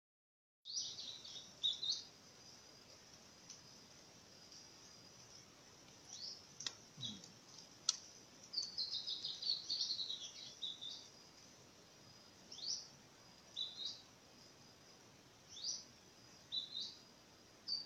Pijuí Negruzco (Synallaxis cinerascens)
Nombre en inglés: Grey-bellied Spinetail
Localidad o área protegida: Parque Provincial Cruce Caballero
Condición: Silvestre
Certeza: Vocalización Grabada